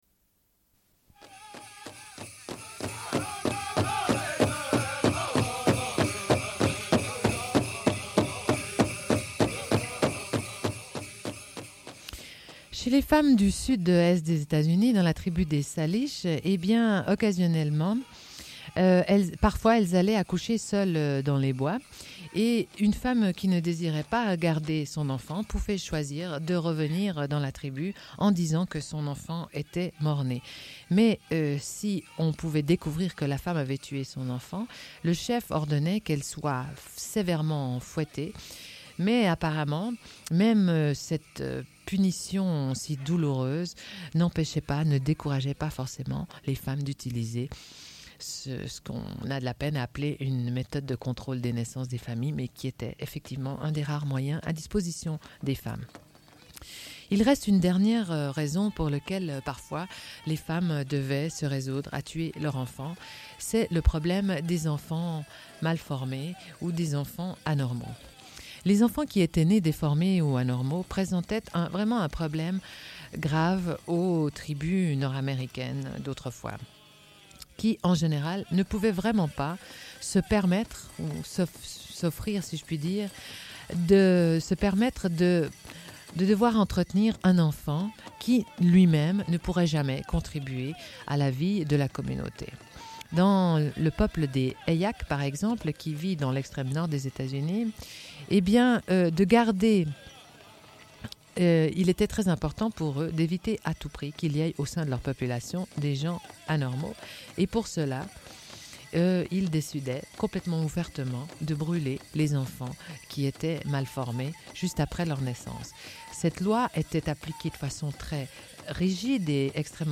Une cassette audio, face A00.31:29